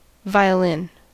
Ääntäminen
Synonyymit fiddle Ääntäminen US US : IPA : /ˌvaɪəˈlɪn/ IPA : [ˌvaɪ̯ɪ̈ˈlɪn] Lyhenteet ja supistumat (musiikki) Vl.